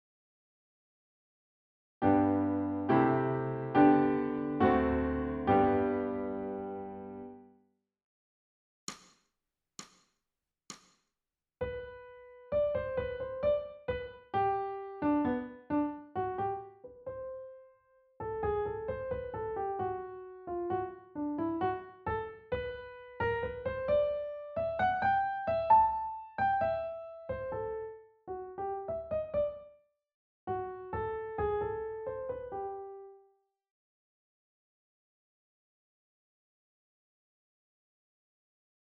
ソルフェージュ 聴音: 2-1-11